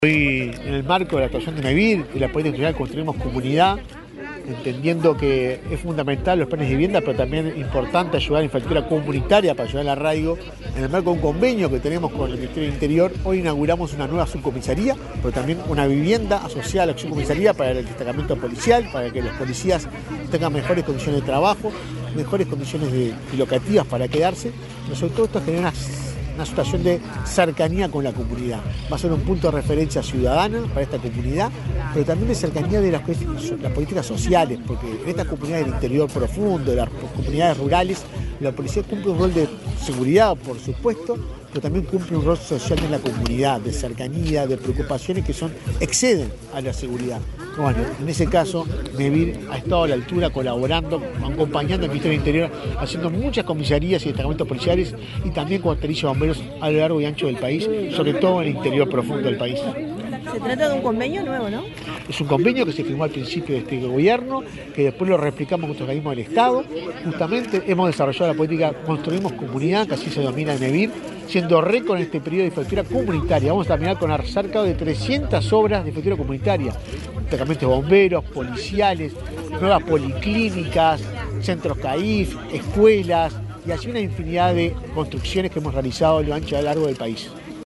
Declaraciones del presidente de Mevir, Juan Pablo Delgado
El presidente de Mevir, Juan Pablo Delgado, dialogó con Comunicación Presidencial, luego de participar en el acto de inauguración de una subcomisaría